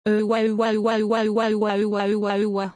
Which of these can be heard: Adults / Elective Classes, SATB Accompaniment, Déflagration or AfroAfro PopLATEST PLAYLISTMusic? Déflagration